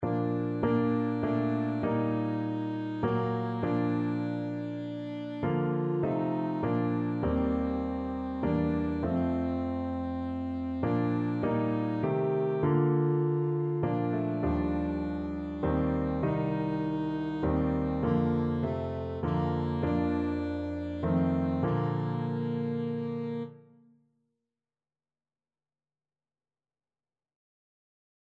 Free Sheet music for Alto Saxophone
Alto Saxophone
3/4 (View more 3/4 Music)
Classical (View more Classical Saxophone Music)